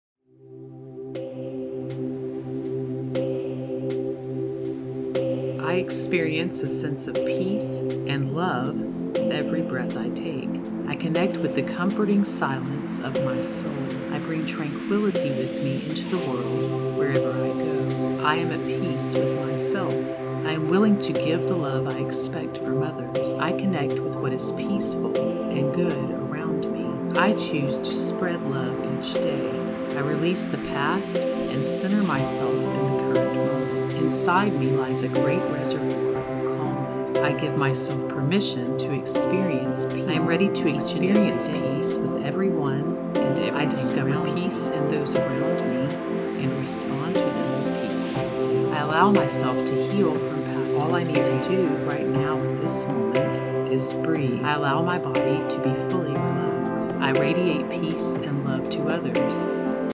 This track includes 50+ affirmations designed to help you release old wounds, become centered in the present moment and balance yourself with the feelings of love and forgiveness so you achieve inner peace and a calm mind. The affirmations on the full 30 minute track are mixed with calming music for a total of over 500+ positive impressions that offer emotional support and encouragement.
affirmations-to-achieve-inner-calm-and-peace-preview.mp3